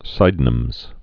(sīdn-əmz)